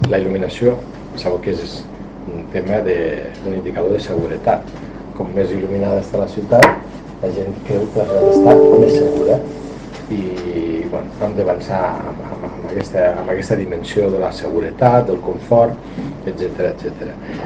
TALL DE VEU ALCALDE LARROSA En l’actualitat, la brigada municipal continua treballant per a complir l’objectiu de tenir el 100% de punts de llum de la ciutat en format LED.